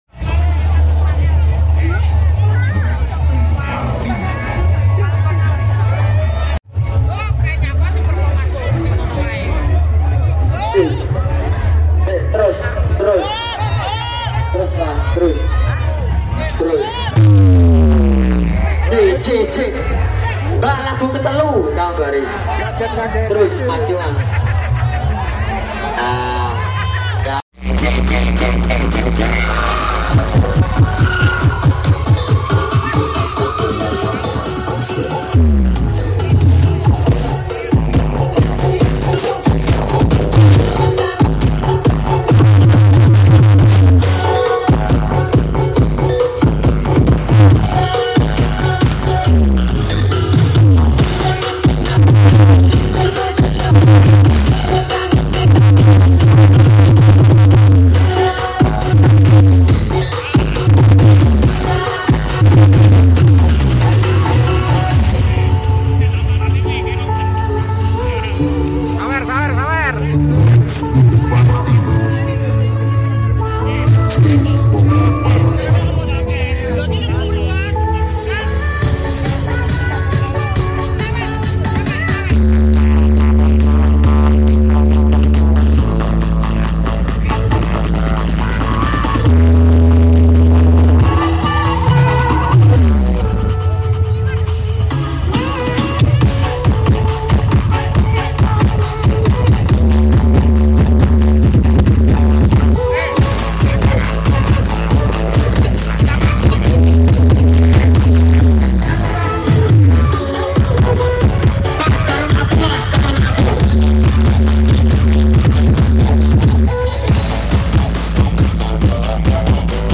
FALCON AUDIO Karnaval Bagorejo.